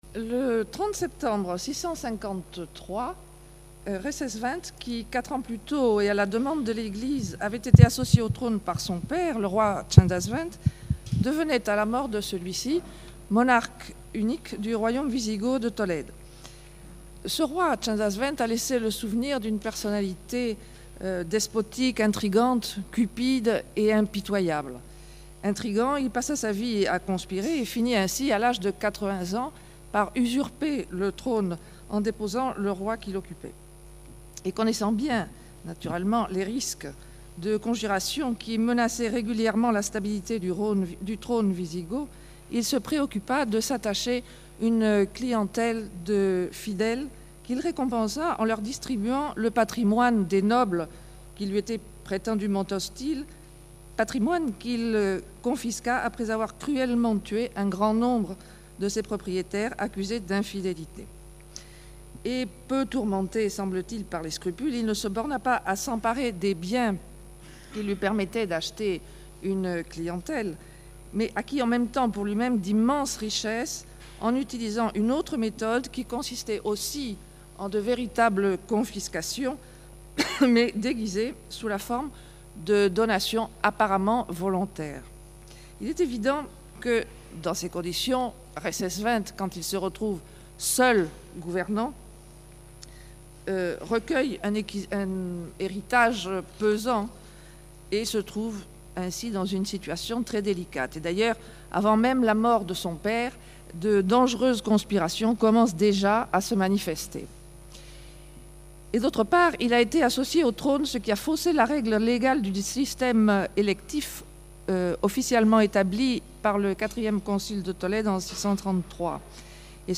Du 1er au 4 juin dernier se sont déroulées à la Faculté de Droit de Tours, les Journées internationales de la Société d'Histoire du Droit, association scientifique internationale plus que centenaire. Le thème qui avait été proposé par notre Faculté était la Responsabilité.